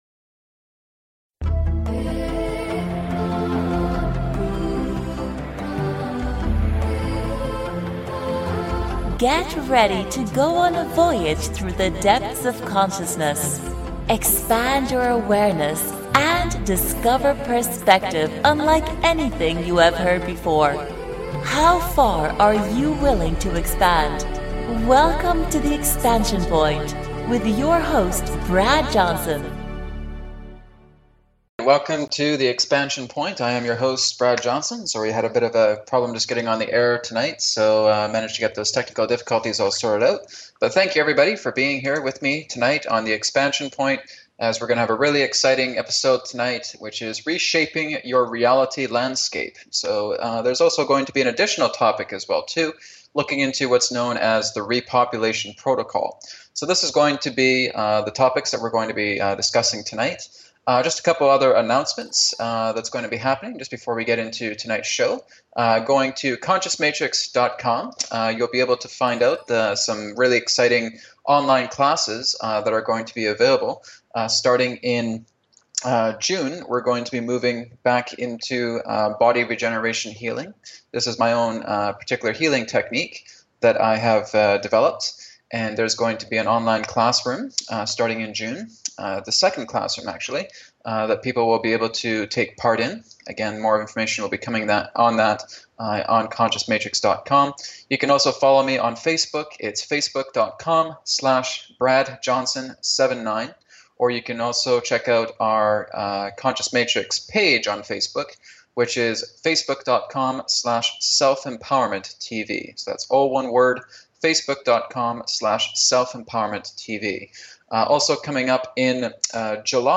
Headlined Show, The Expansion Point May 21, 2014